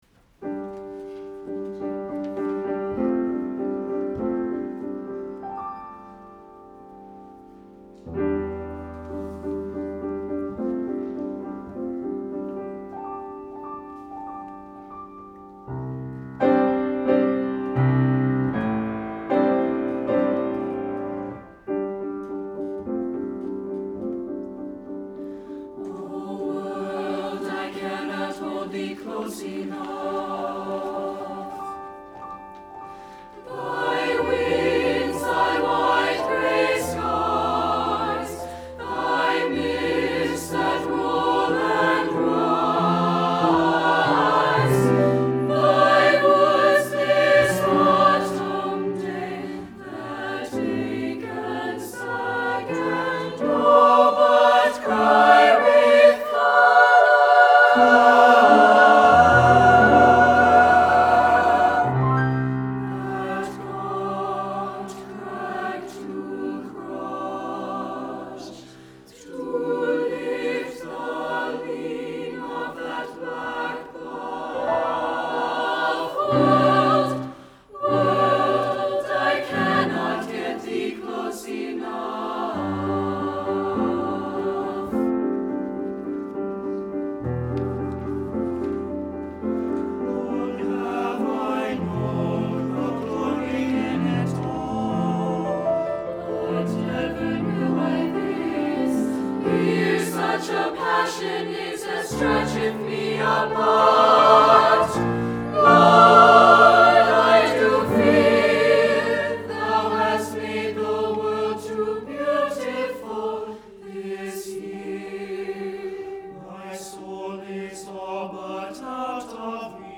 for SATB Chorus and Piano (2012-18)
choral version